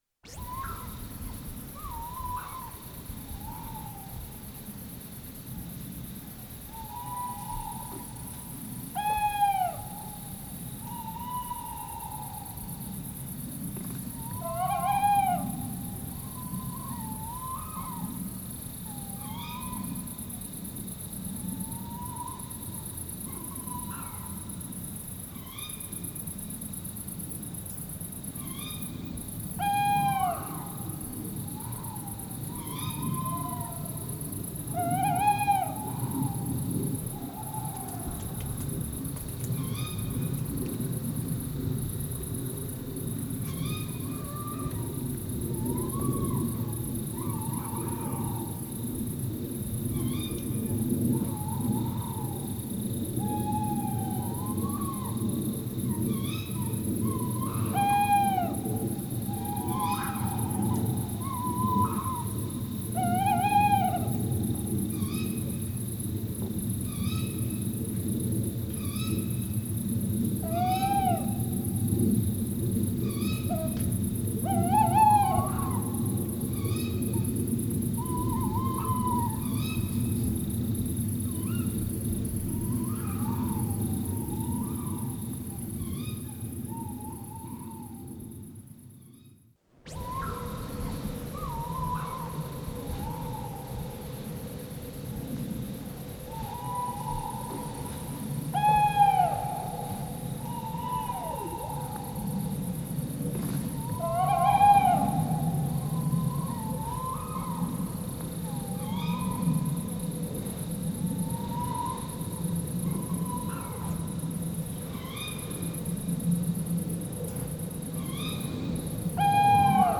Download – 1001. field recording into Silhouette, 01